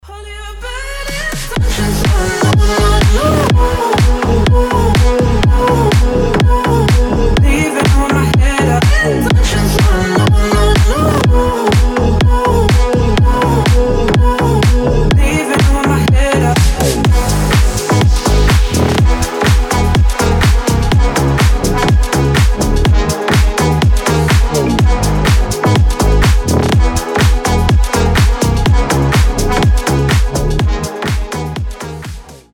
• Качество: 320, Stereo
громкие
Electronic
EDM
future house
энергичные
Классная музыка в стиле фьюче хаус